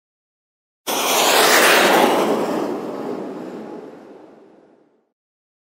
دانلود آهنگ شلیک موشک از افکت صوتی حمل و نقل
جلوه های صوتی
دانلود صدای شلیک موشک از ساعد نیوز با لینک مستقیم و کیفیت بالا